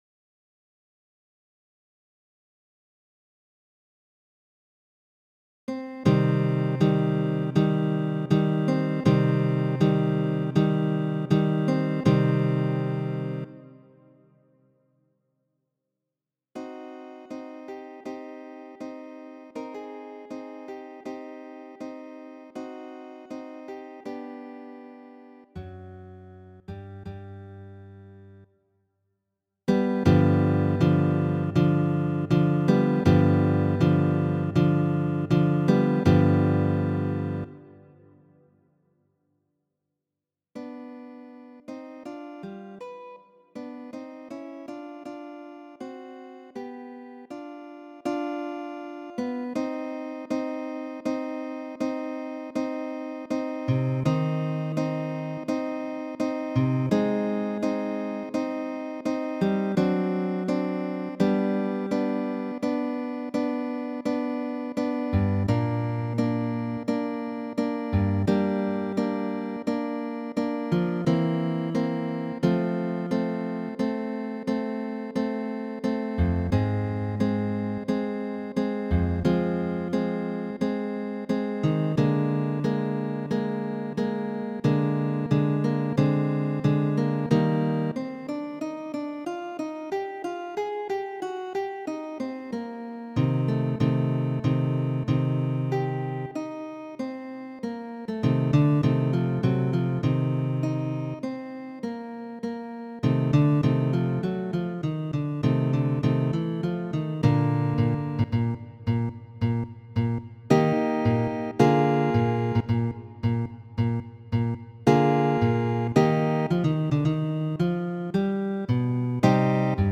Duan Grandan Sonaton, opus 25, ni povas malkovri profundan, seriozan, orĥestran muzikon tie, kie nur gitaro sonas.
Sed en la grandaj sonatoj de Sor ni aŭdas almenaŭ kvar apartajn melodiojn, krome de la akordoj sesnotaj.